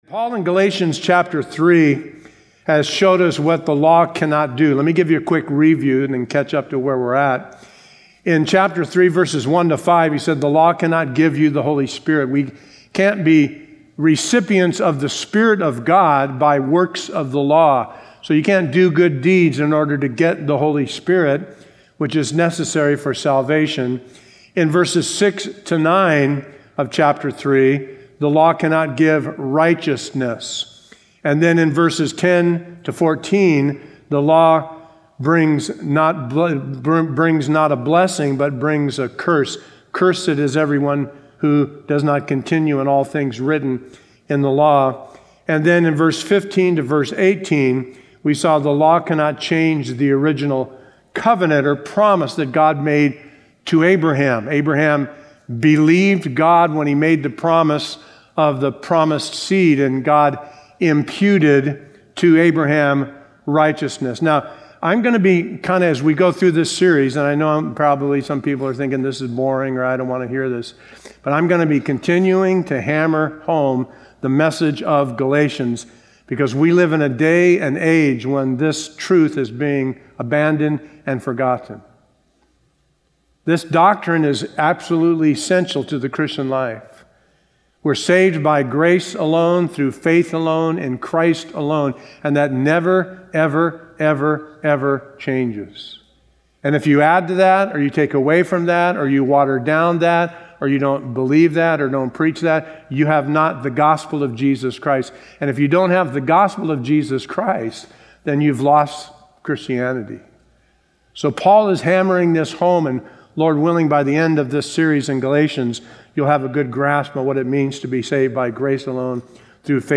Sermon info